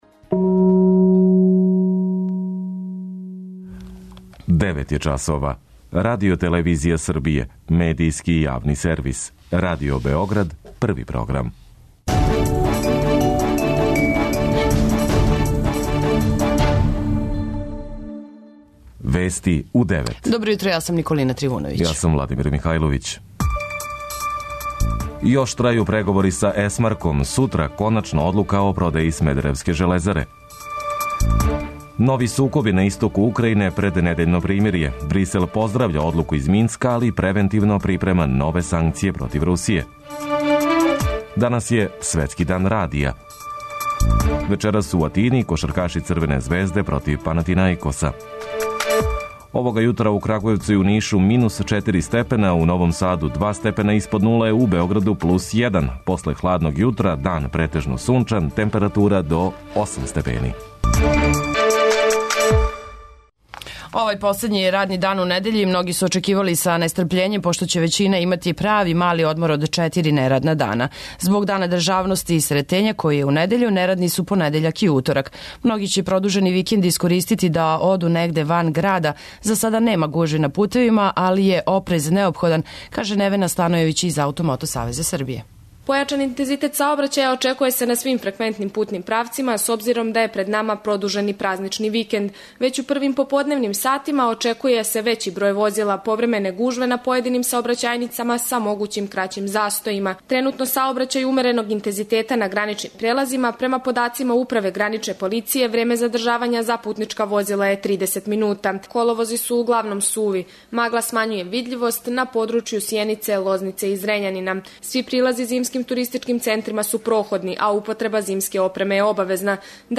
преузми : 10.09 MB Вести у 9 Autor: разни аутори Преглед најважнијиx информација из земље из света.